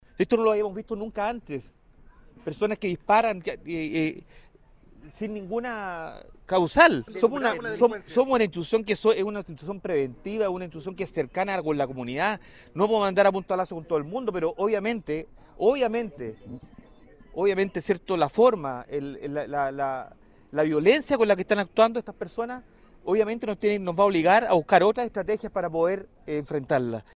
En medio de los esfuerzos médicos, fue en propio general director de la institución, Ricardo Yañez, quien con lágrimas en los ojos y una voz entrecortada condenó lo sucedido y argumentó la necesidad de buscar nuevas estrategias para abordar la delincuencia.